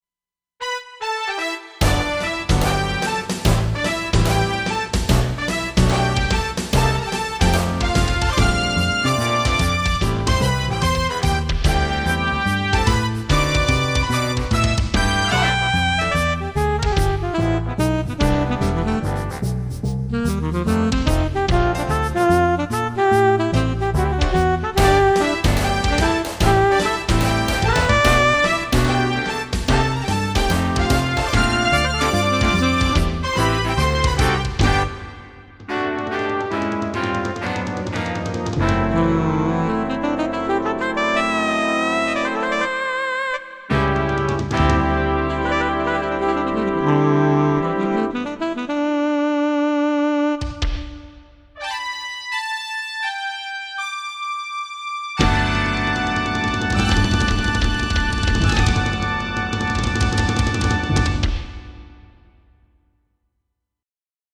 MSC-11S; MPC-11S Brass